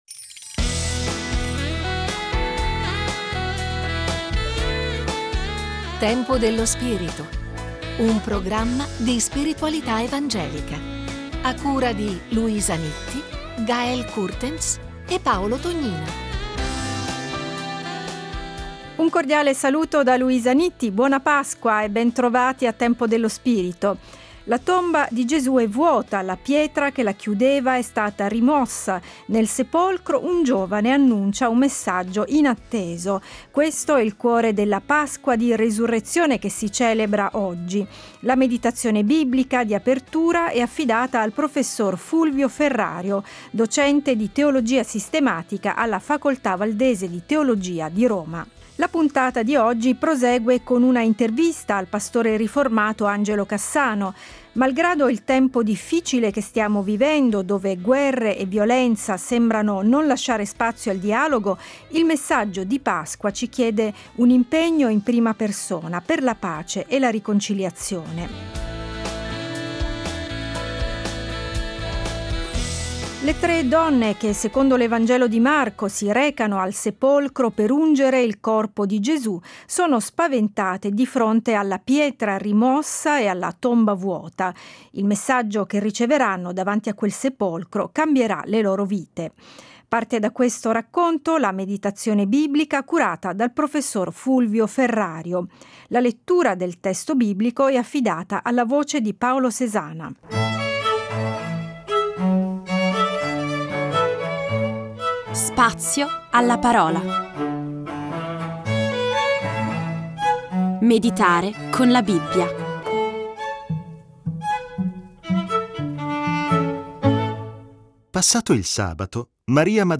Ma il messaggio del Cristo risorto ci sollecita ad agire e impegnarci per il bene del mondo. Scopri la serie Tempo dello spirito Settimanale di spiritualità evangelica.